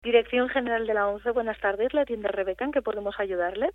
y melódica formato MP3 audio(0,07 MB) suena su voz al otro lado del hilo telefónico - “Dirección General de la ONCE.